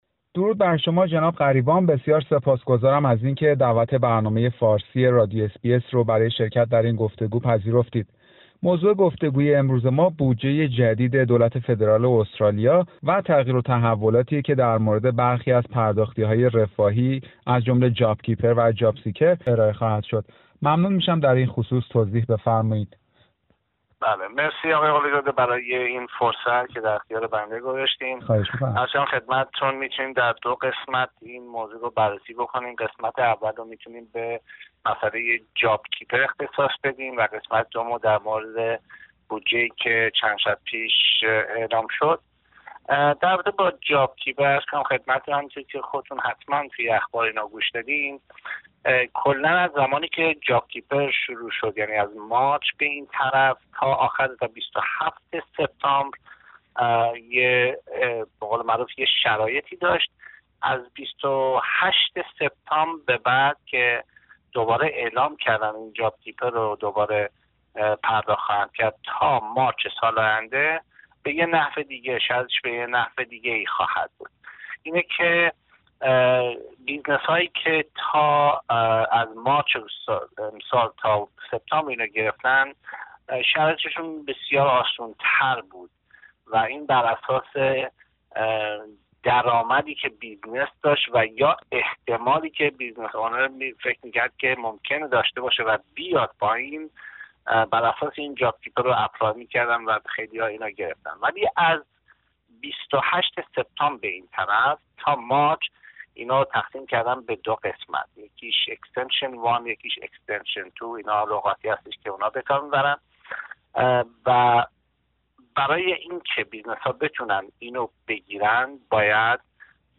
گفتگو با یک حسابدار در مورد بودجه جدید استرالیا، پرداختی های رفاهی و کاهش های مالیاتی